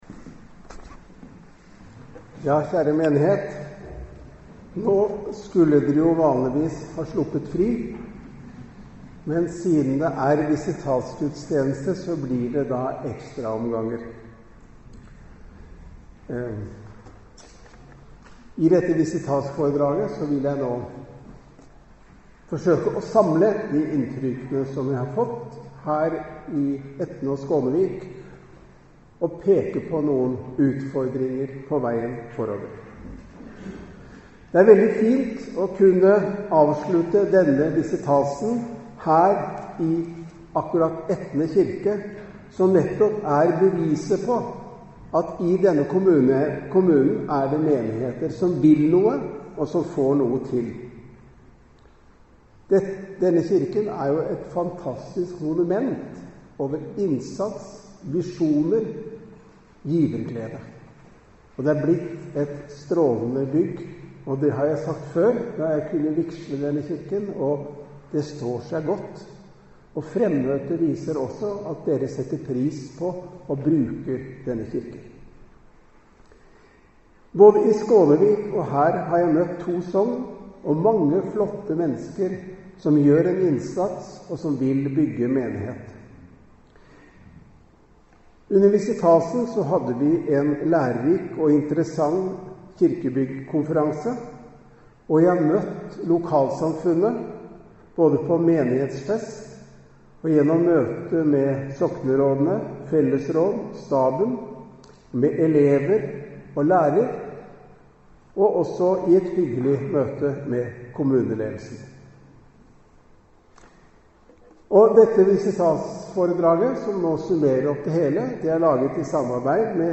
Visitasføredrag i Etne - Bjørgvin bispedøme